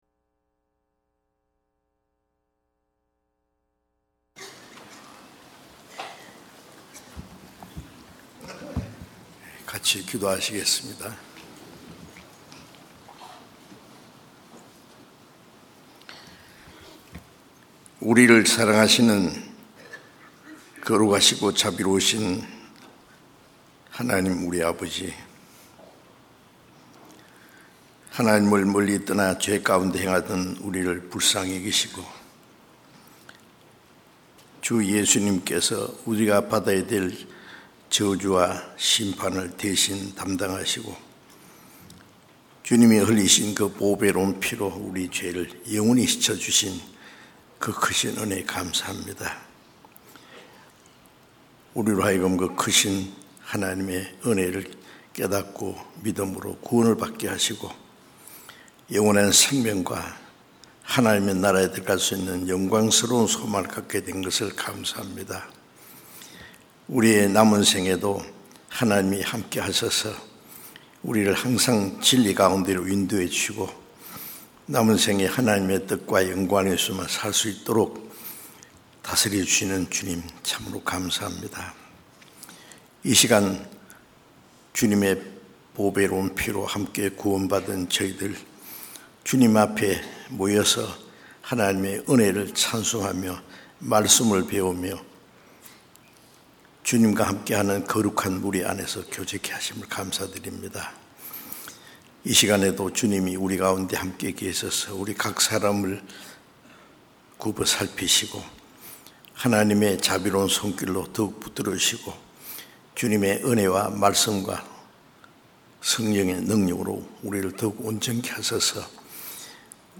주일설교수요설교 (Audio)